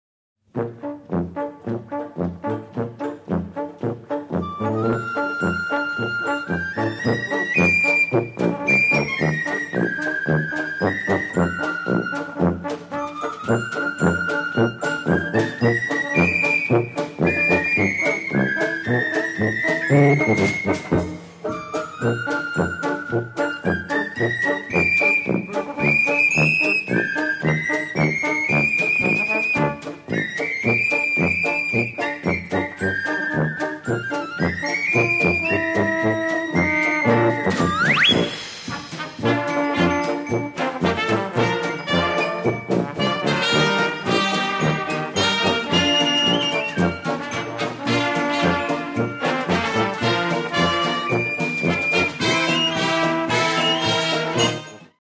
Marches et galops
interpreté par la Fanfare Octave Callot